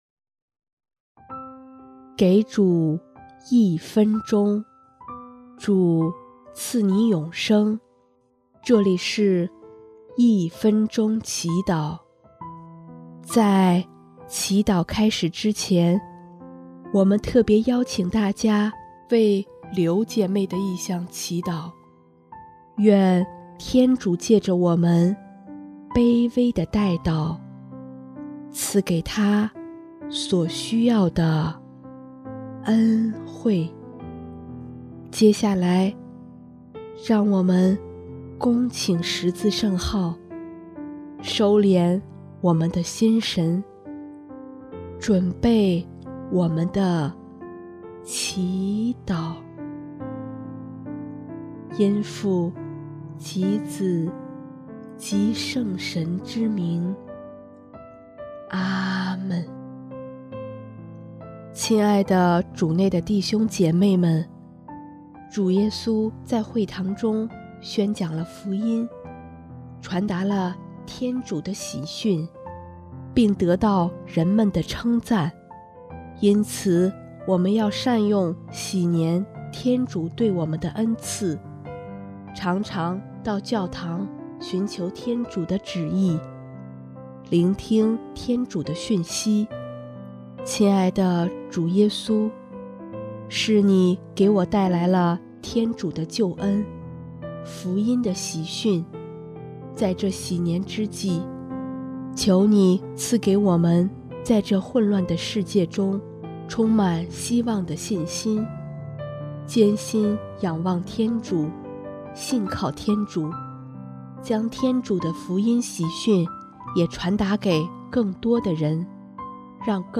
【一分钟祈祷】|1月9日 天主的喜讯